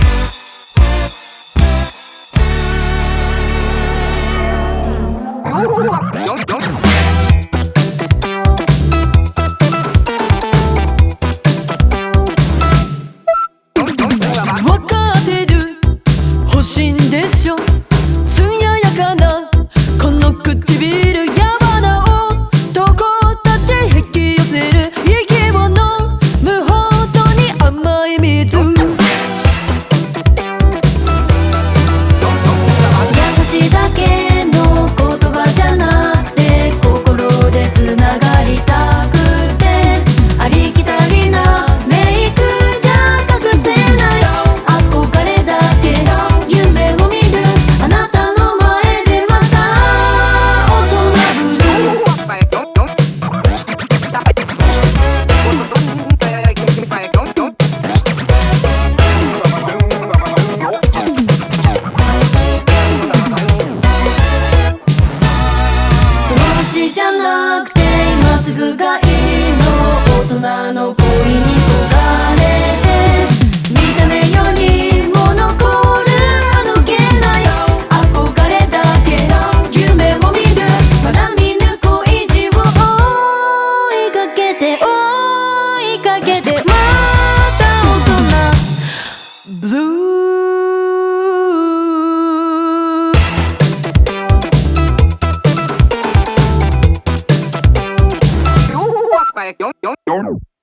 BPM130
Audio QualityPerfect (High Quality)